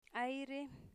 aire air Part of Speech noun Acquisition Method Elicitations Etymology Spanish Phonological Representation 'aiɾe air aire [Spanish] samay [Quichua] (Part of) Synonym (for) samay Example 85: Cuidadpica aireca contaminashcami.